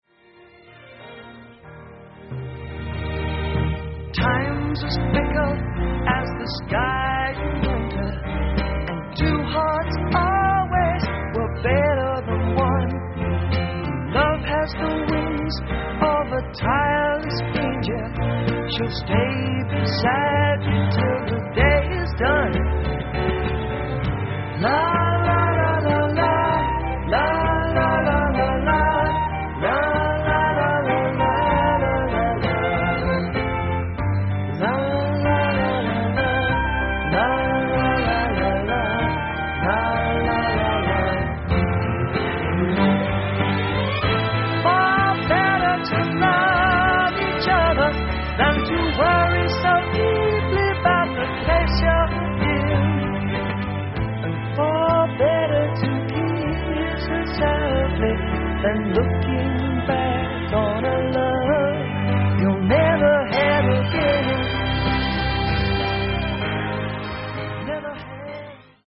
Musicland / Munich, Germany
Orchestral Arrangements